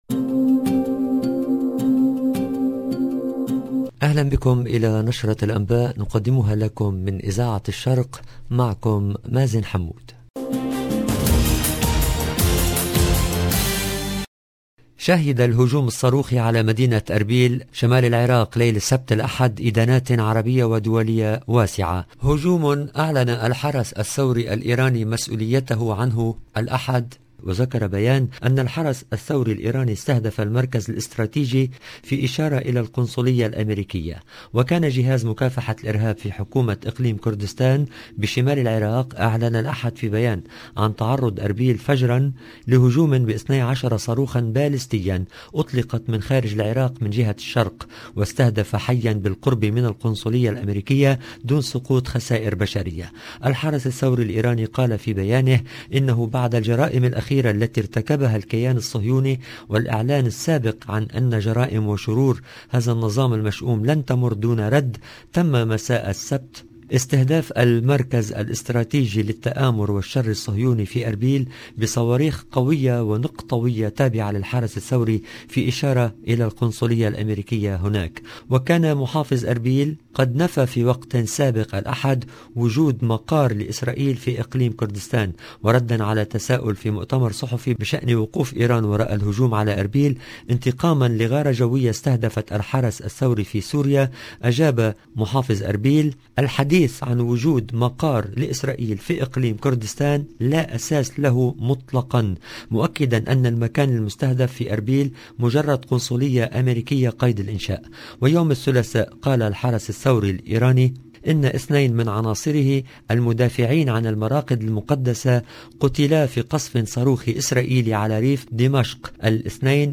EDITION DU JOURNAL DU SOIR EN LANGUE ARABE DU 13/3/2022